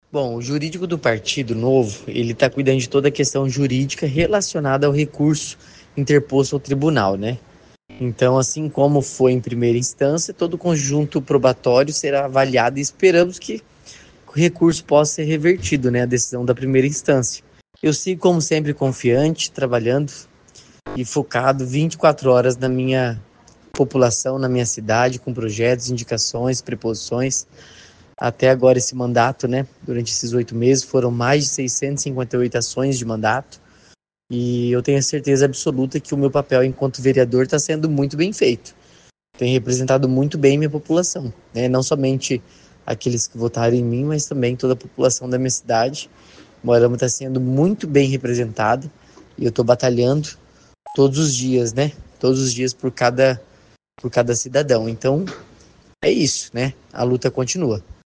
(atualizado às 18h): O vereador Lucas Grau comentou o assunto: